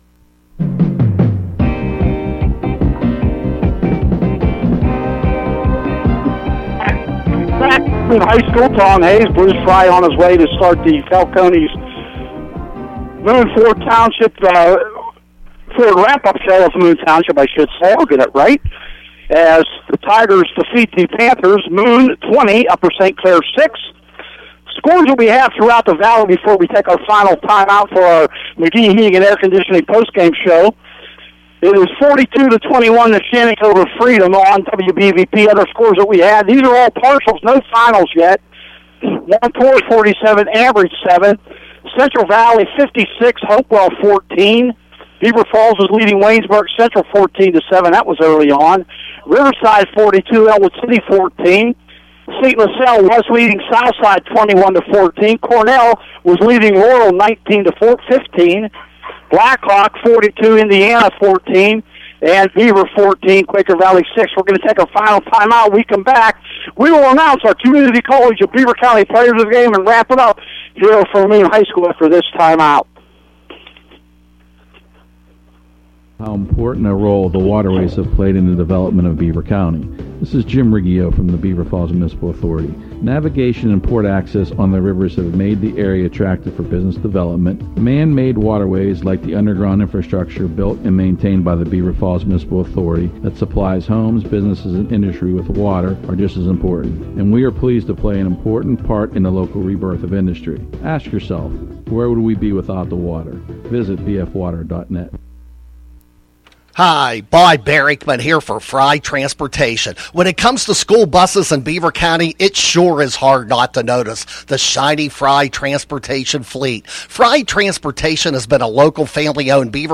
You can listen to the post game here: